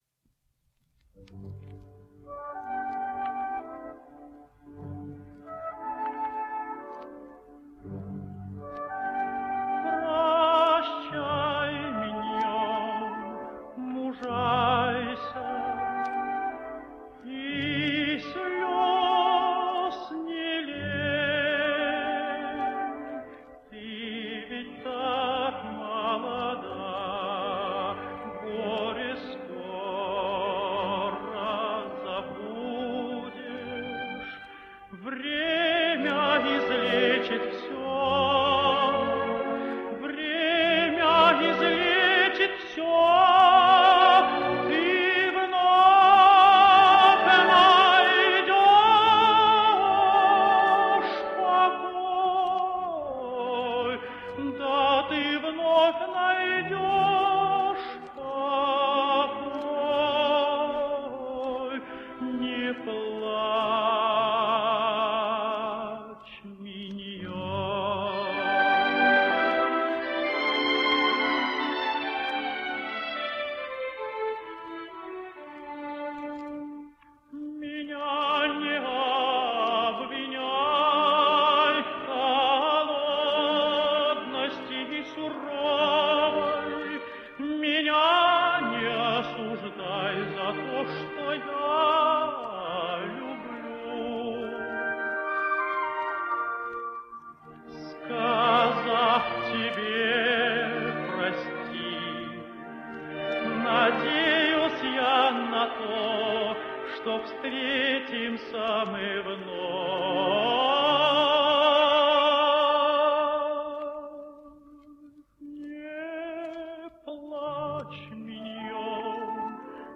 Russian Tenor.
And then the ideal Mignon from Tomas Obrer, a perfect vehicle for this elegant tenor.